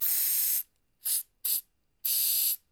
HAIRSPRY 2-S.WAV